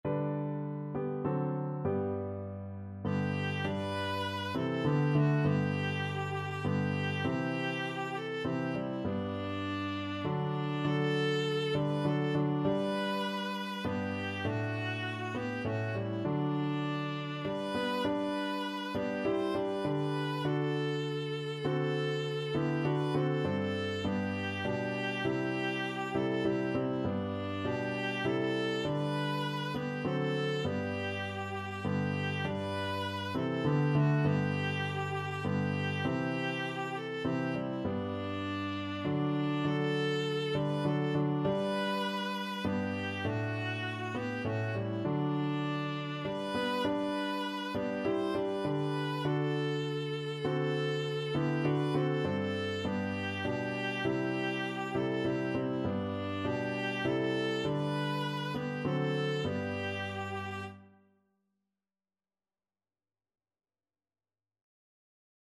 3/4 (View more 3/4 Music)
Classical (View more Classical Viola Music)